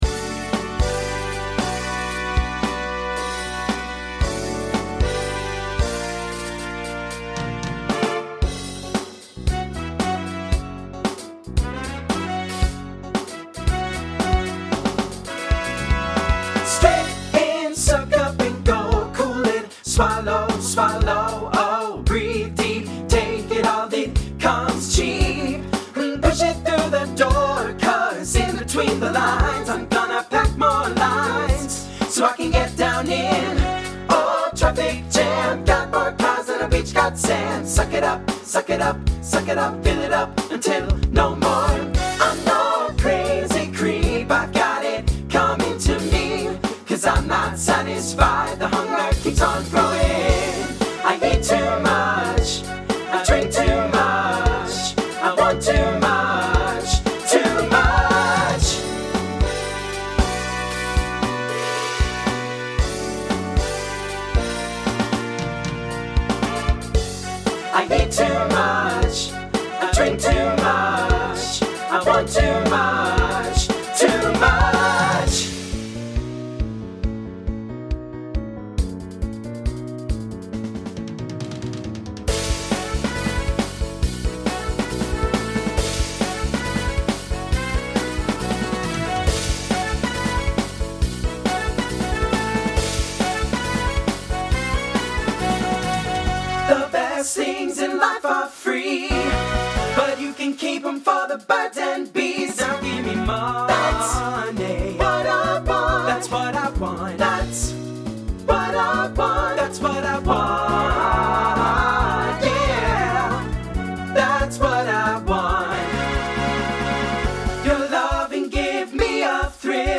SATB Instrumental combo Genre Rock